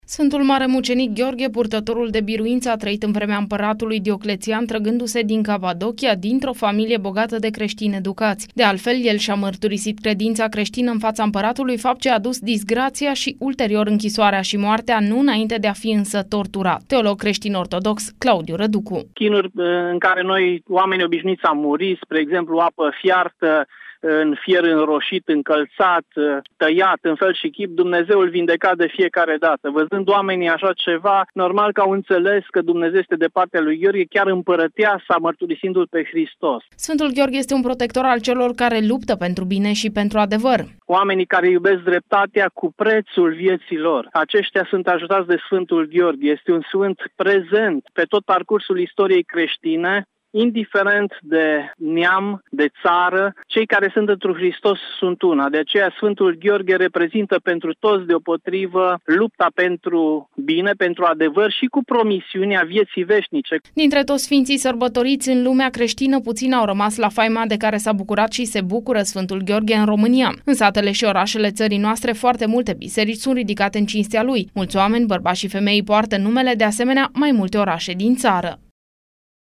reportajul în format audio